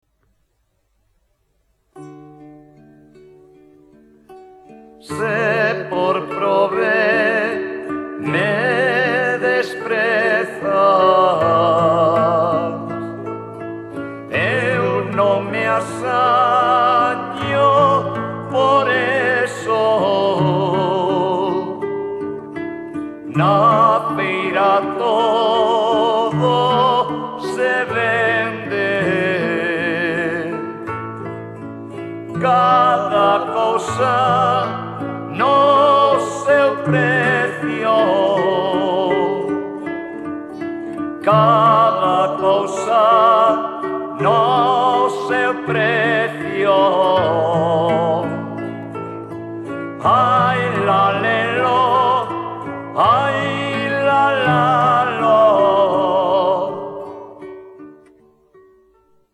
Música:Popular